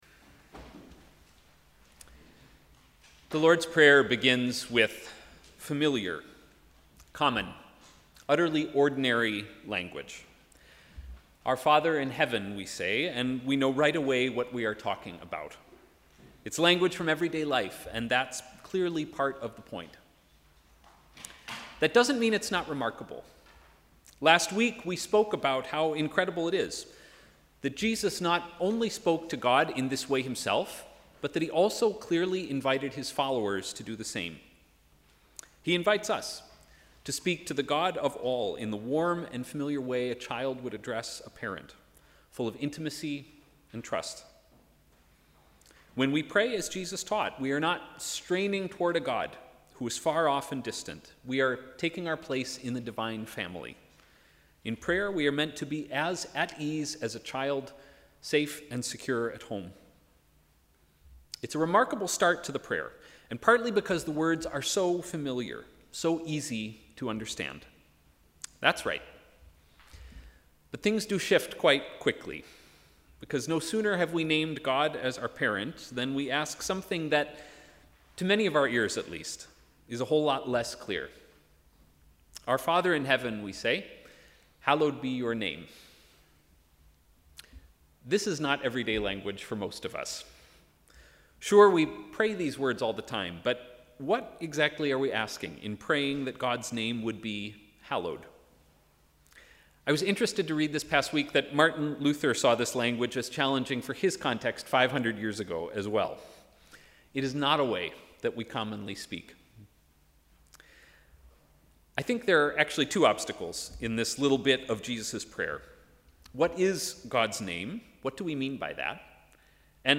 Sermon: ‘Hallowed be Your Name’